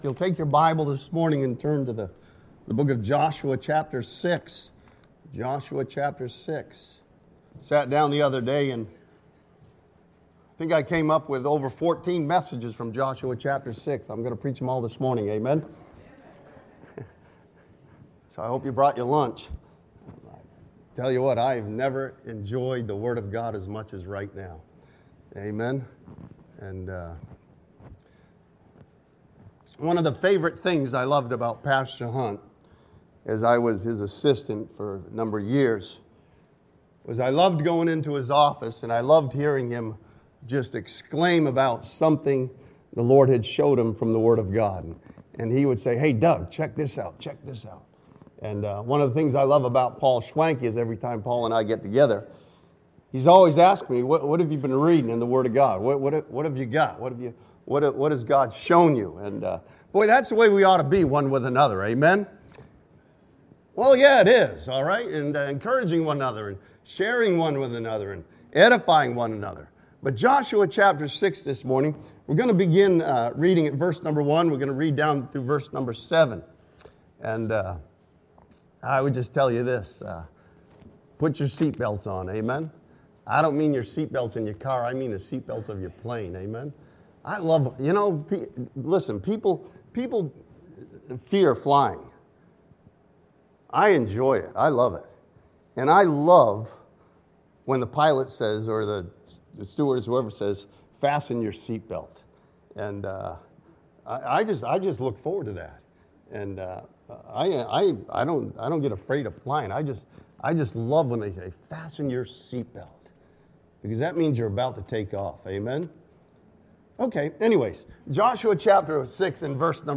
This sermon from Joshua chapter 6 studies the phrase "compass the city" and finds comfort and encouragement in its meaning.